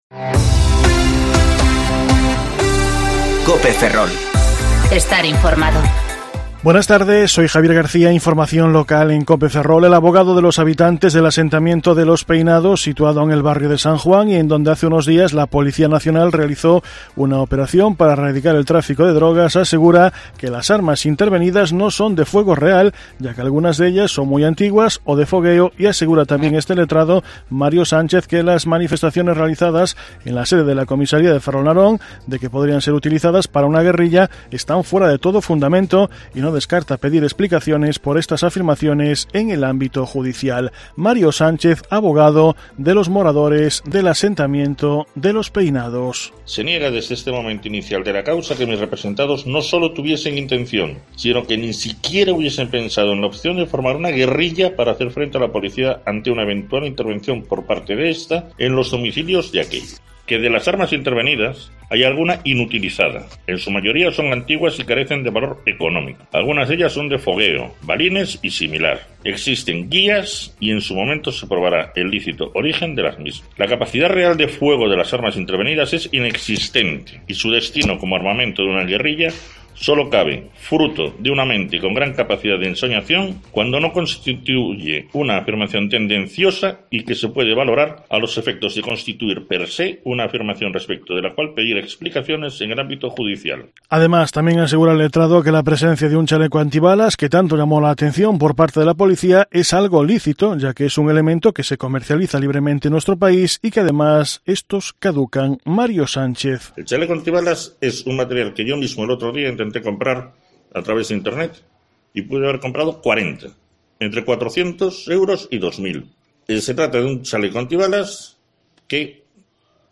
Informativo Mediodía Cope Ferrol 31/10/2019 (De 14.20 a 14.30 horas)